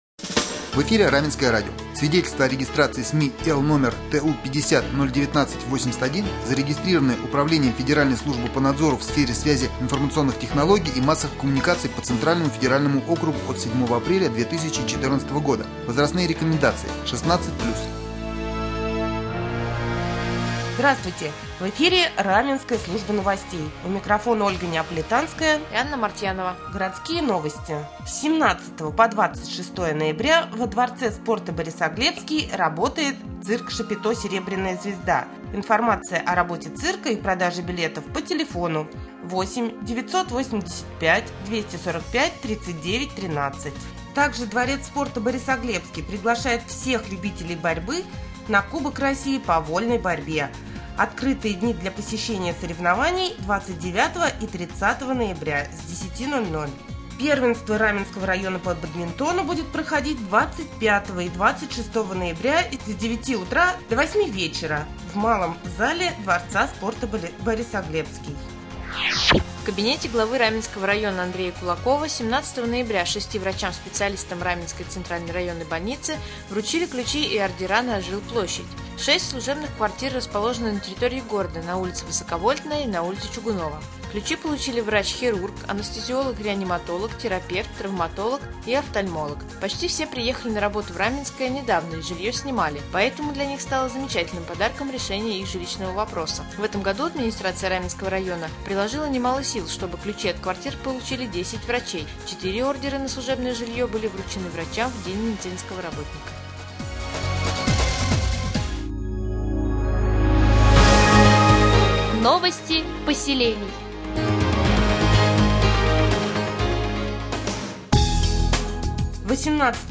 Сегодня в новостном выпуске на Раменском радио Вы узнаете, какие мероприятия пройдут во дворце спорта «Борисоглебский» в ближайшее время, где получили новые квартиры 6 врачей Раменском ЦРБ, а также последние областные новости и новости соседних районов.